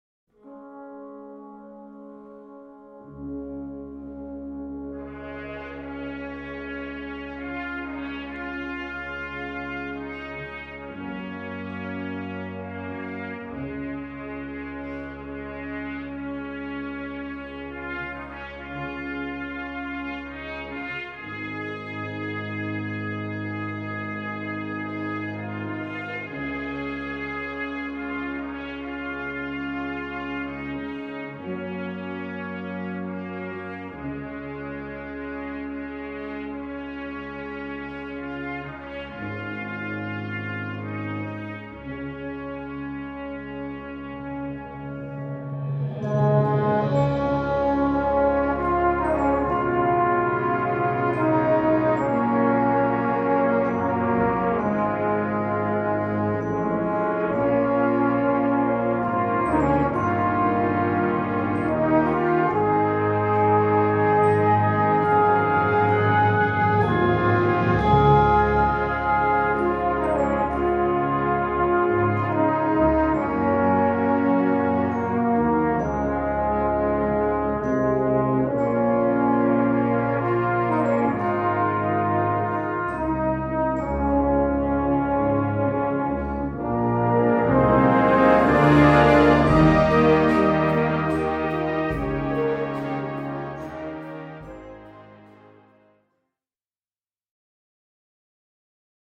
3:30 Minuten Besetzung: Blasorchester PDF